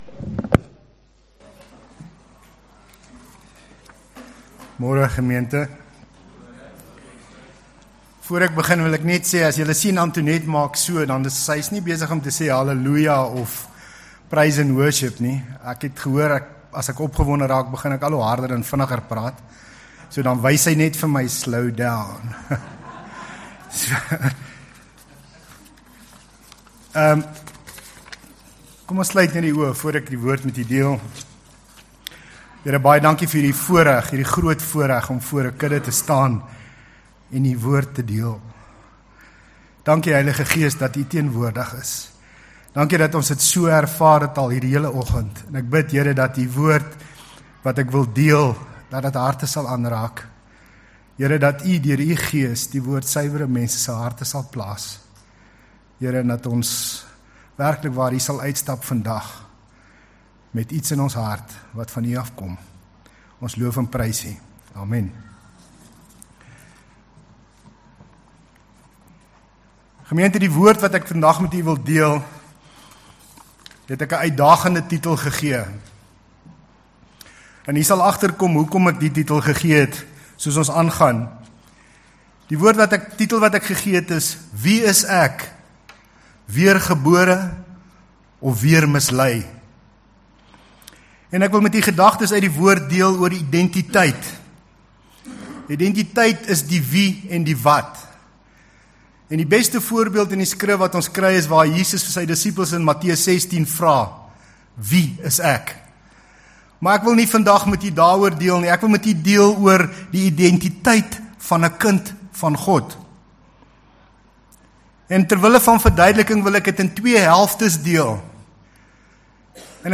John 14:1 Service Type: Morning Weer gebore of weer mislei?